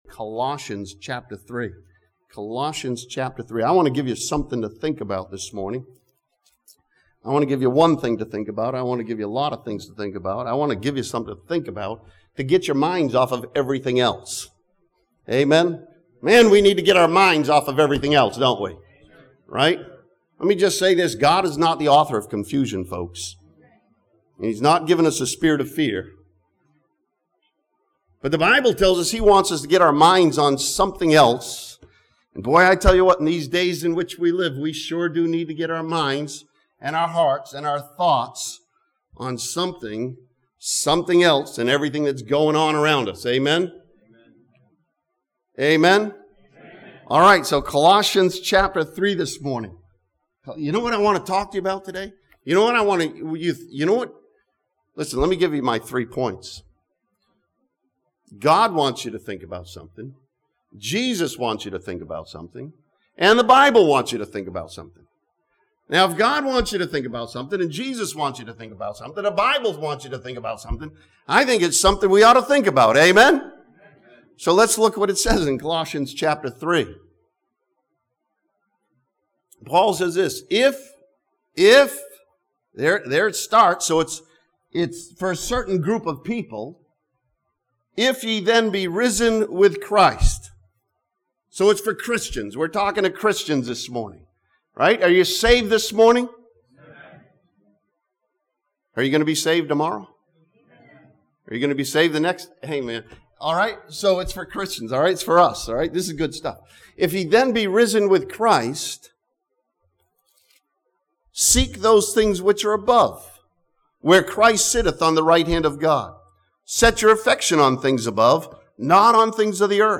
This sermon from Colossians chapter 3 challenges believers to be more heavenly minded and keep their minds fixed on things above.